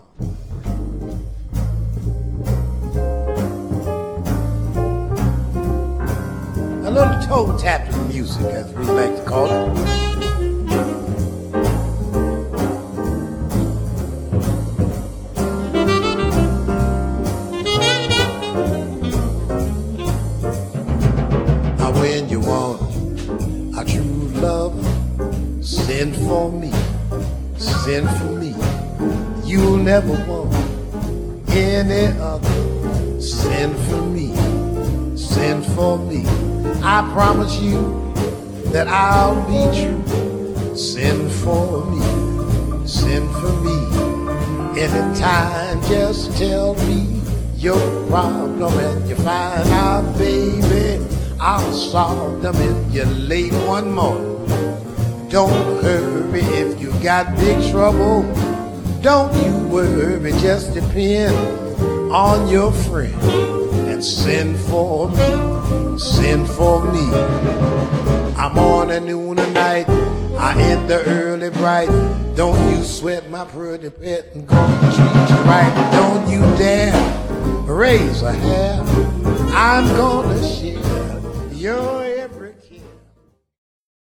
Jazz na CD
tenor sax
guitar
drums
sytý a vyzrálý baryton